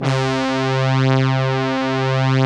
Index of /90_sSampleCDs/Club-50 - Foundations Roland/SYN_xAna Syns 1/SYN_xJX Brass X2